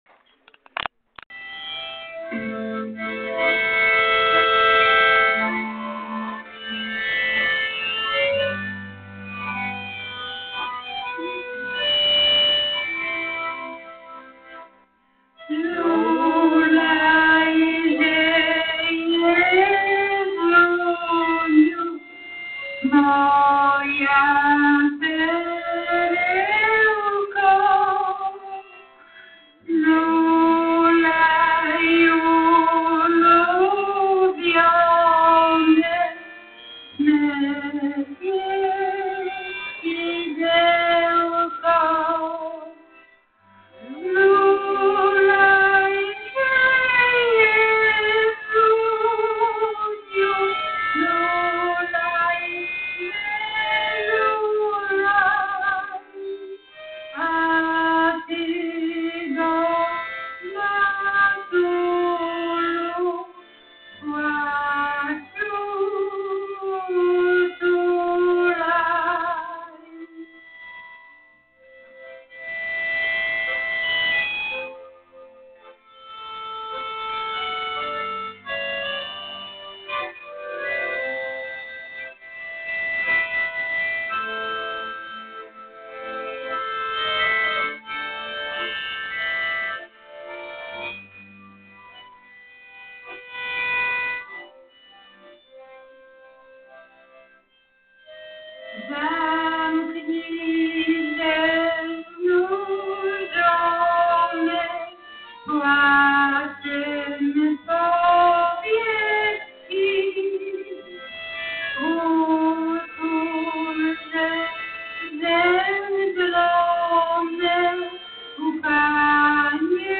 Oto wybrane fragmenty spotkania (przepraszamy za usterki w nagraniu) oraz teksty kolęd do ew. pobrania i foto-galeria.